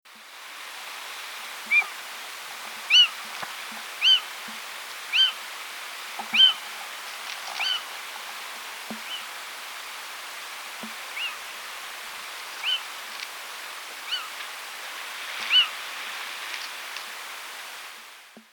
Cris-chouette-cheveche.mp3